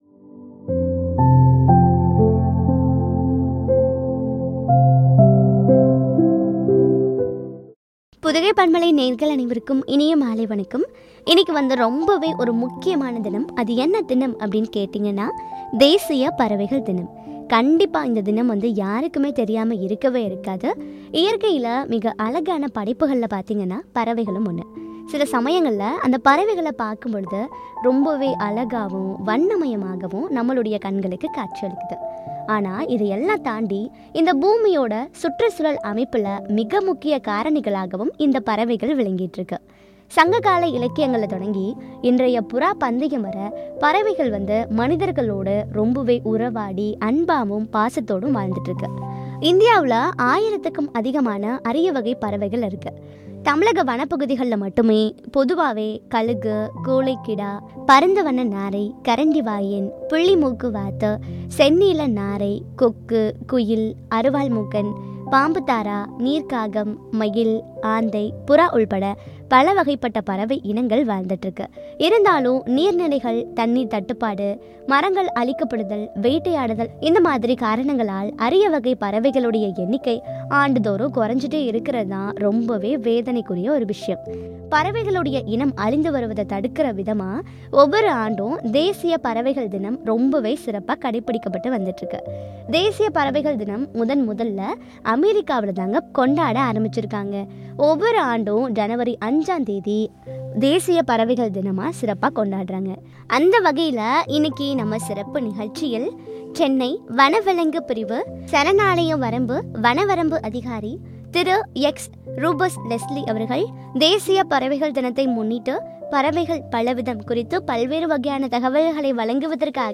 “பறவைகள் பலவிதம்” குறித்து வழங்கிய உரையாடல்.